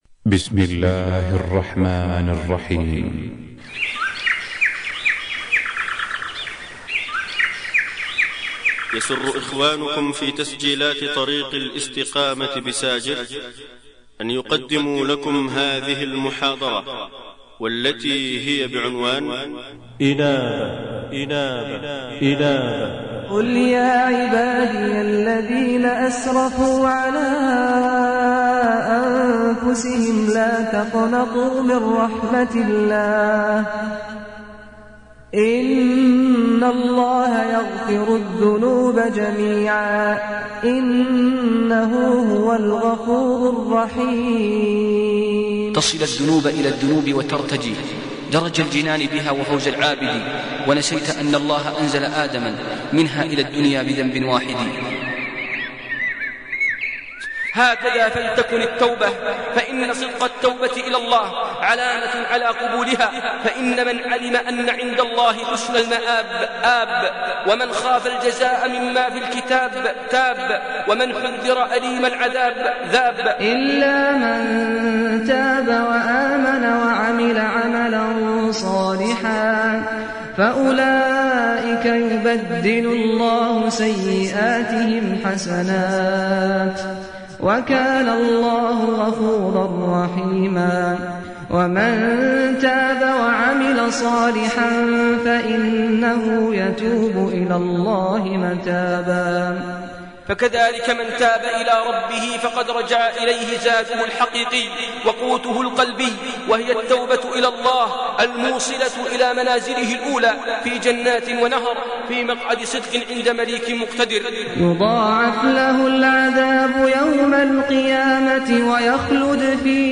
الإنابة إلى الله - من أروع المحاضرات